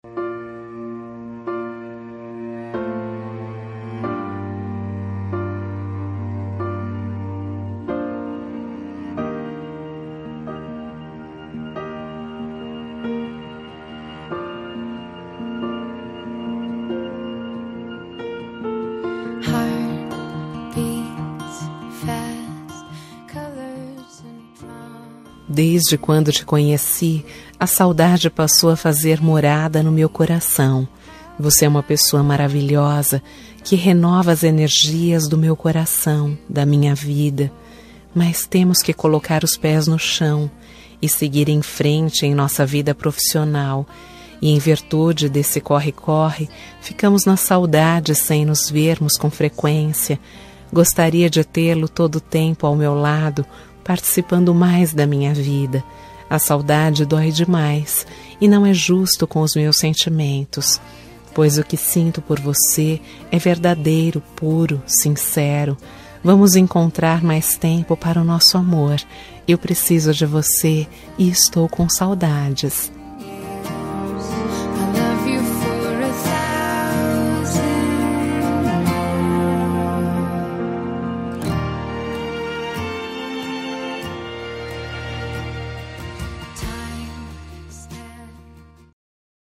Telemensagem Romântica Distante – Voz Feminino – Cód: 201894